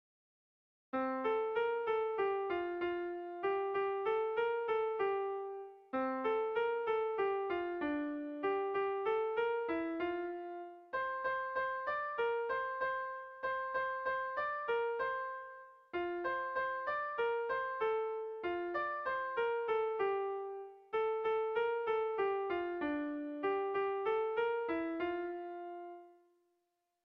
Air de bertsos - Voir fiche   Pour savoir plus sur cette section
Hamarreko txikia (hg) / Bost puntuko txikia (ip)
ABDEB